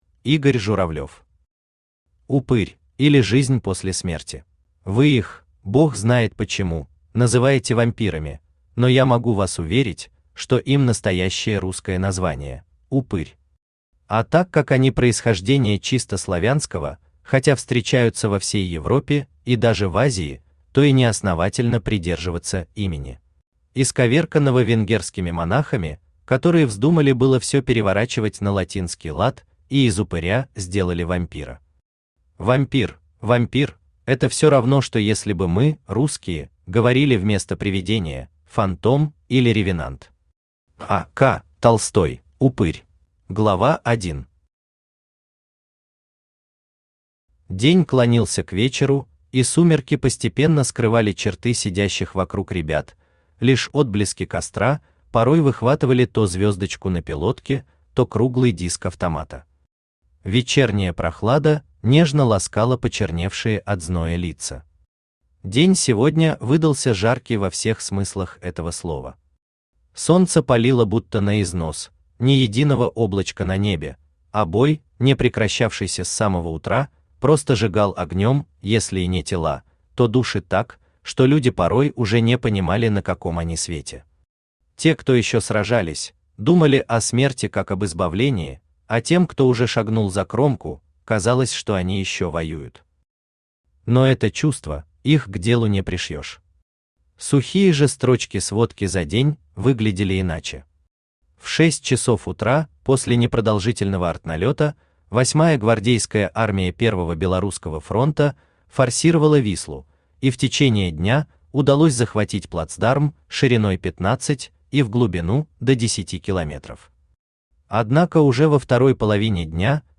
Аудиокнига Упырь, или Жизнь после смерти | Библиотека аудиокниг
Aудиокнига Упырь, или Жизнь после смерти Автор Игорь Журавлев Читает аудиокнигу Авточтец ЛитРес.